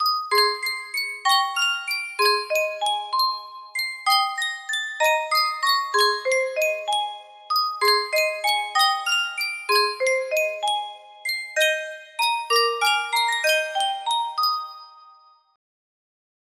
Sankyo Music Box - I'll Take You Home Again Kathleen FPV music box melody
Full range 60